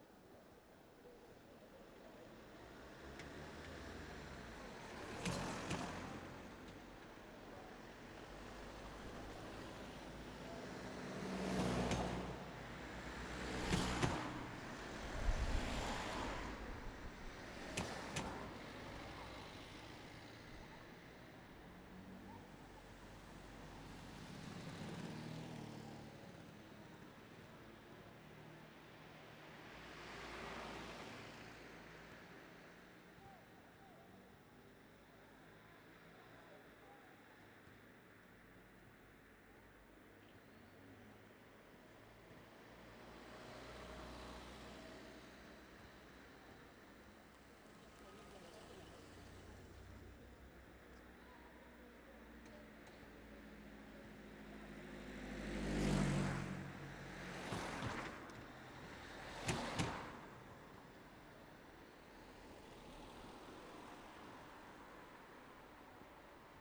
Tráfico denso
Grabación de una calle con bastante tráfico.